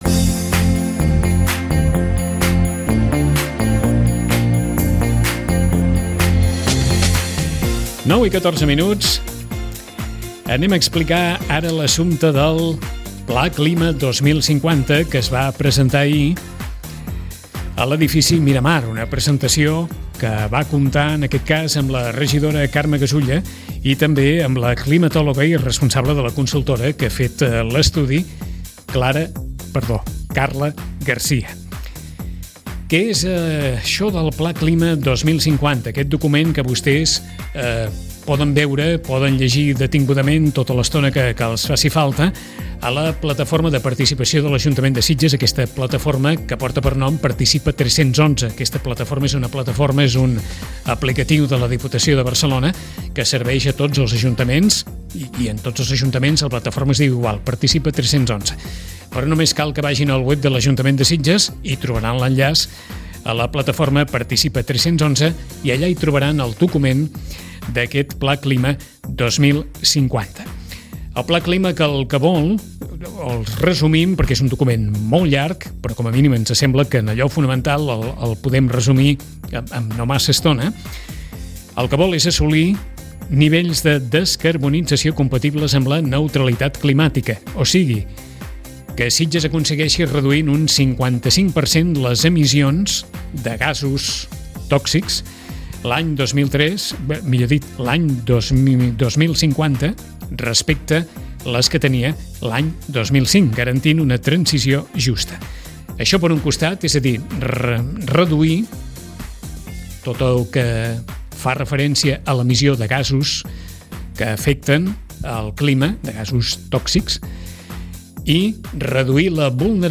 escoltareu les declaracions de la regidora Carme Gasulla